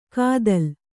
♪ kādal